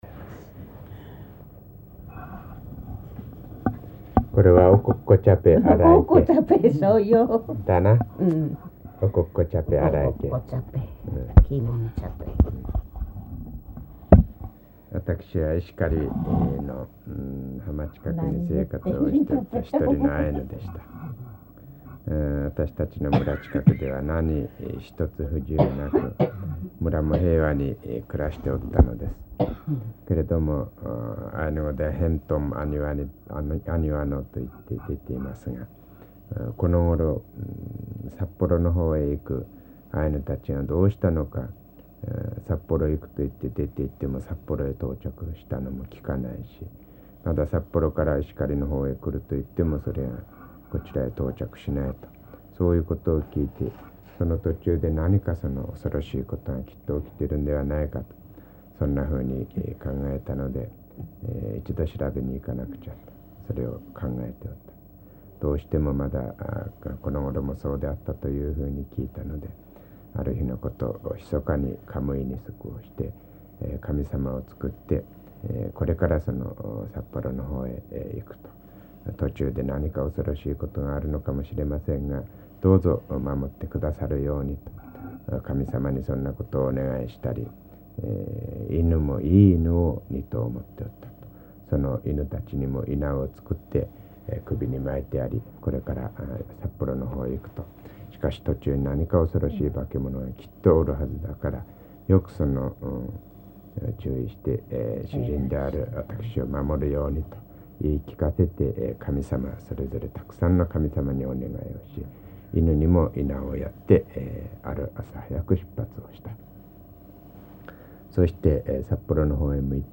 [3-5 解説 commentary] 日本語音声 6:11